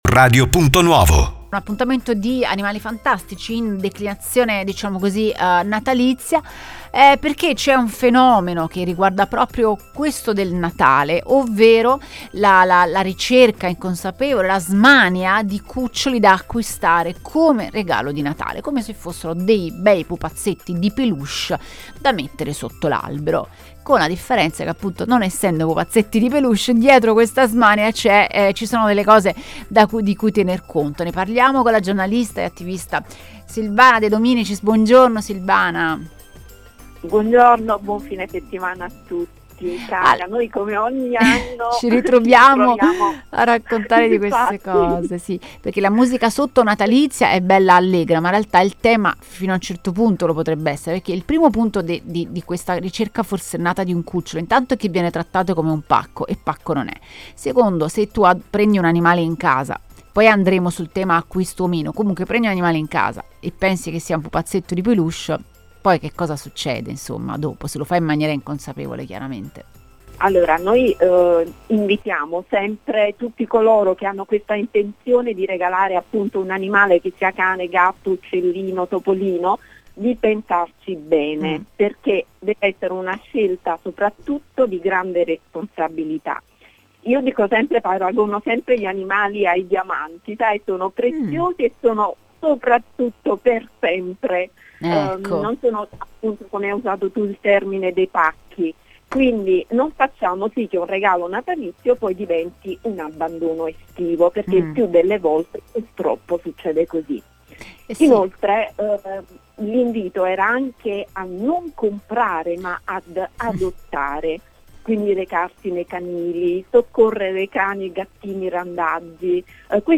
Ne abbiamo parlato ad Animali Fantastici con la giornalista e attivista per gli animali